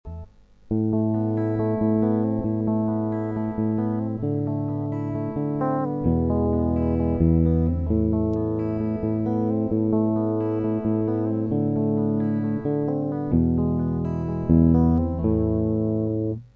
Вступление и куплет до слов "я откыл окно" играем так: